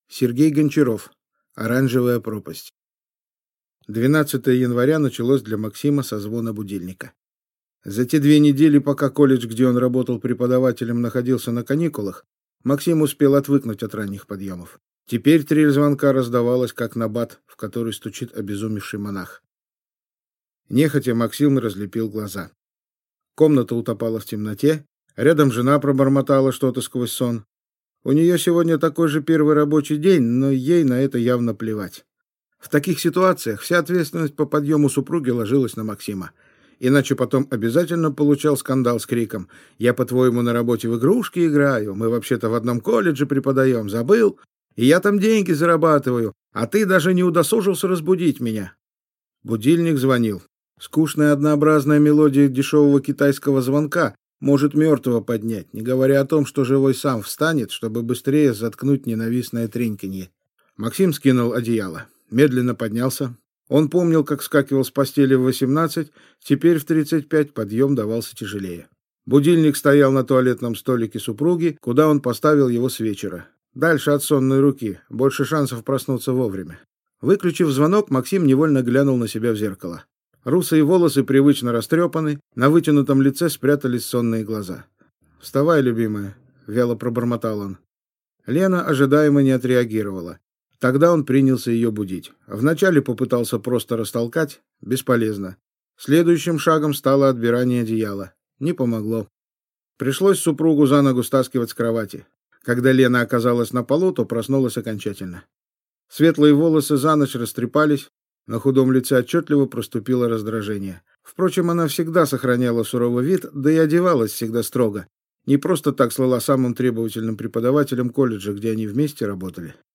Аудиокнига Оранжевая пропасть | Библиотека аудиокниг